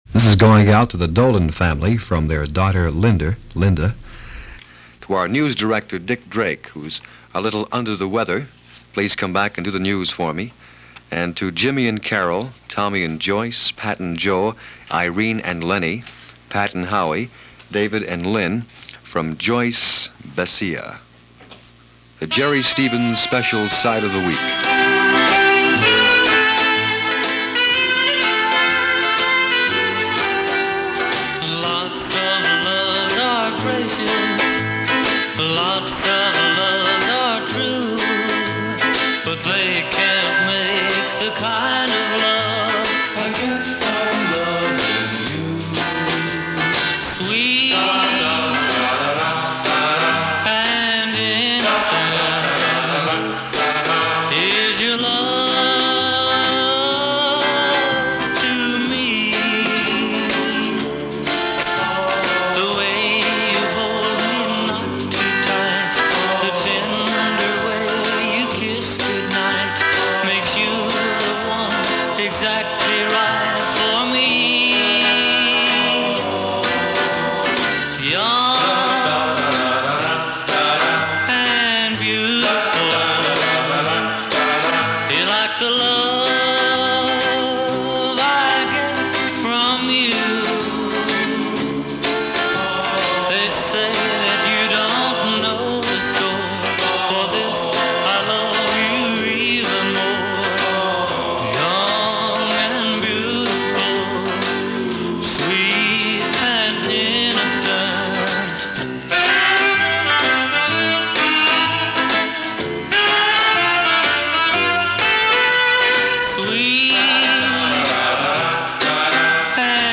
But like most Top-40 radio at the time, this was what the mainstream was sounding like – it was interesting in that, even though the emphasis was on Rock n’ Roll, it also had room for other genres which were popular with the 15-25 demographic of the time.